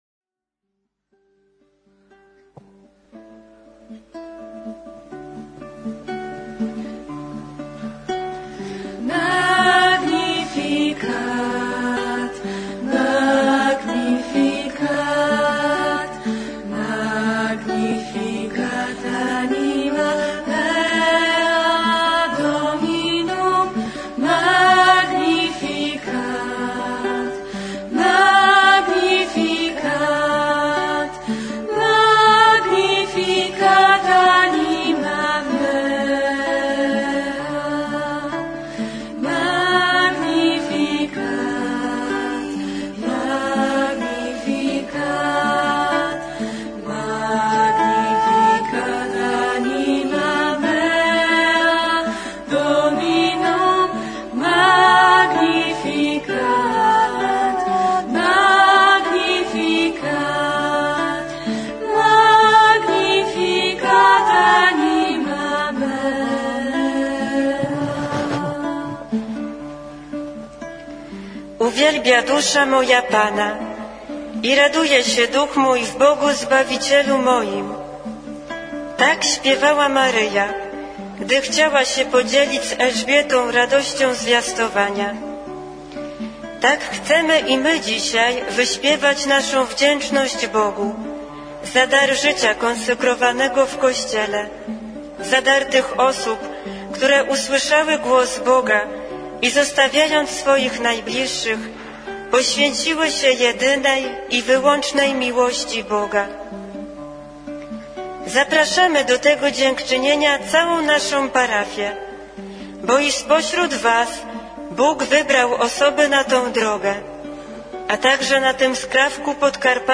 Program słowno-muzyczny na Rok Życia Konsekrowanego
Takie słowa płynęły dziś tj. 7 grudnia 2014 roku z Sanktuarium św. Andrzeja Boboli, przed każdą Mszą Świętą podczas programu słowno-muzycznego, przygotowanego przez Siostry z okazji Roku Życia Konsekrowanego.